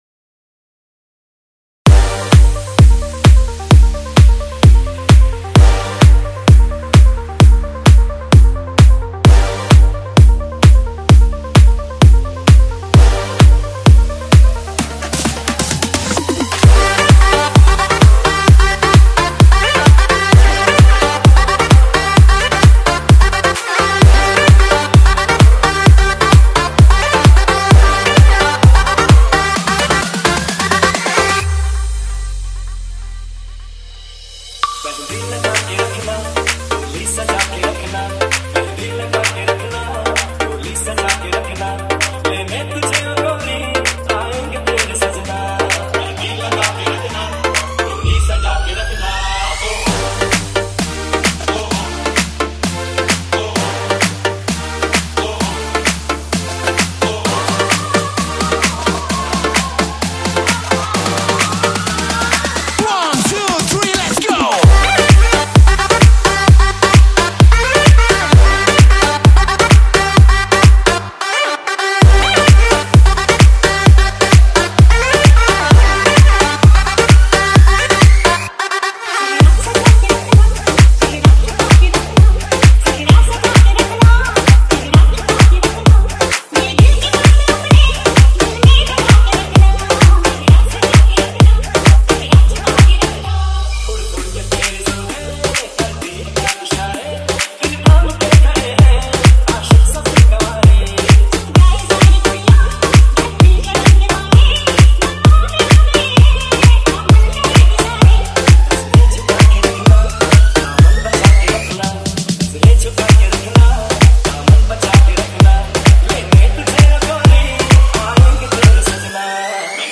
Category : Weeding Special Remix Song